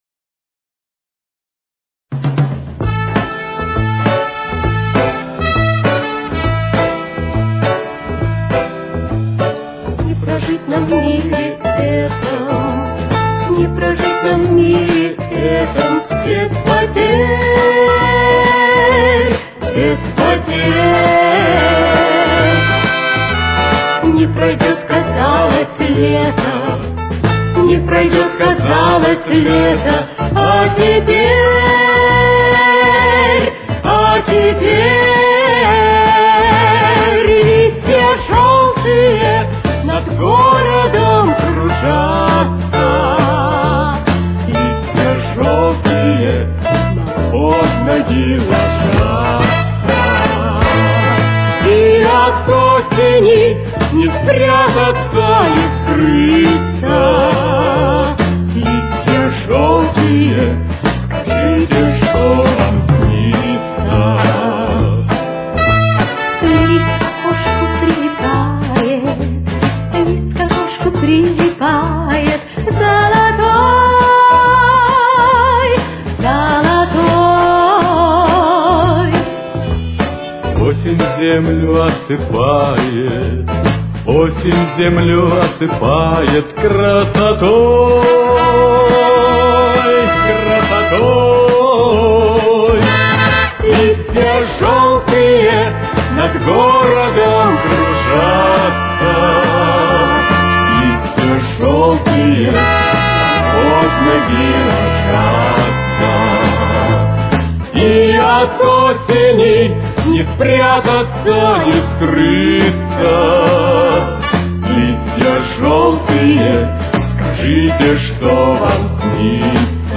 Ля минор. Темп: 139.